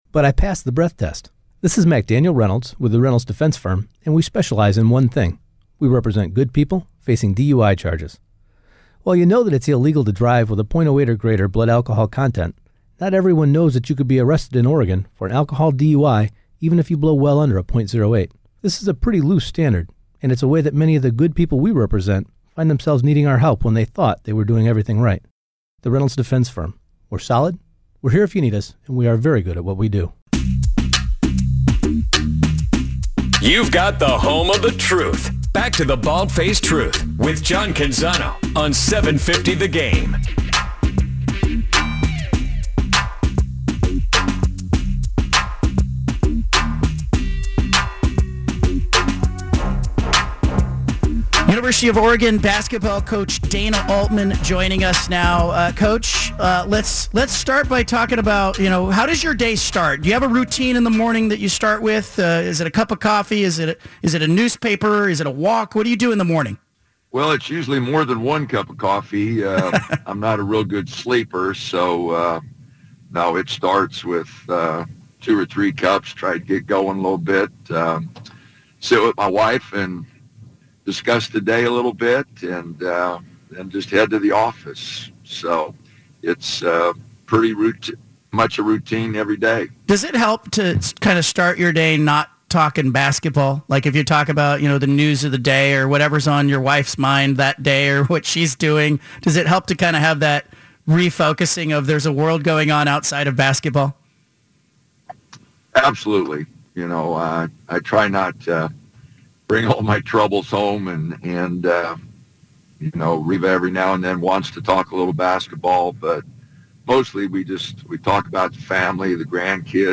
BFT Interview: Dana Altman (; 11 Dec 2025) | Padverb